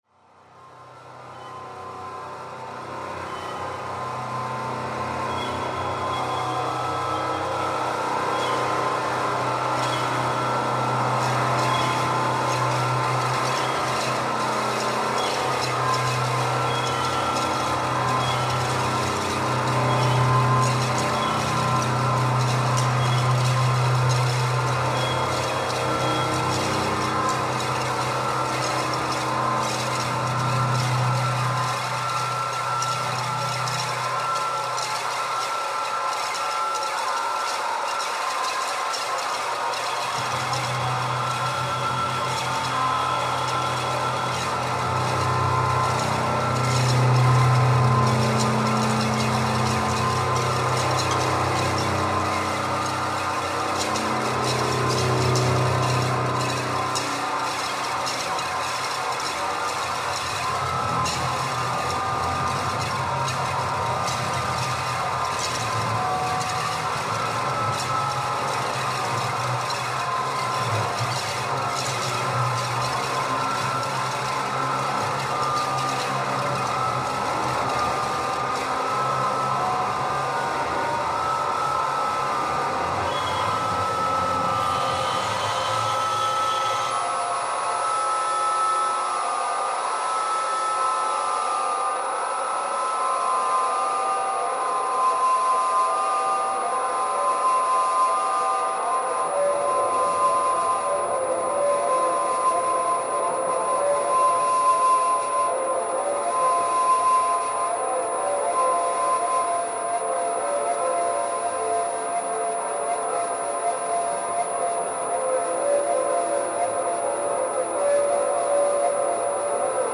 electronics